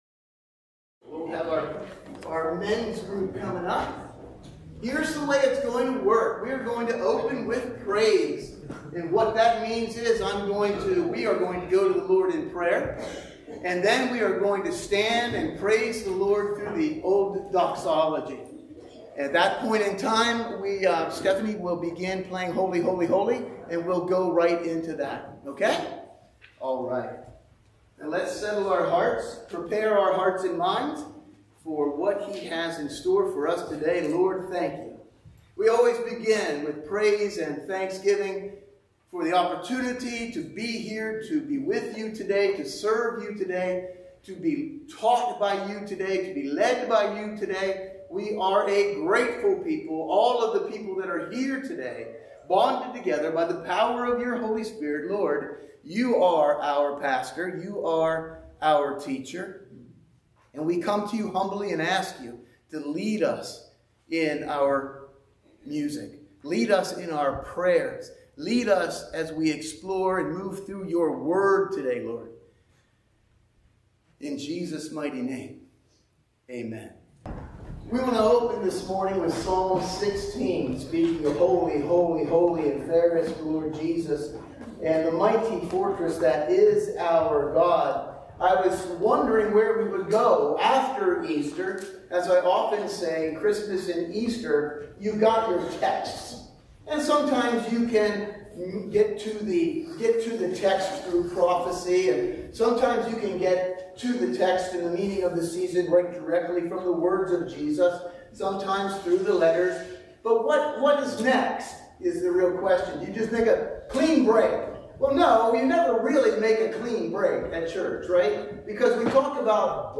Sunday Morning Service – April 7, 2024 – Churchtown Church of God
Sunday-Morning-Service-April-7-2024.mp3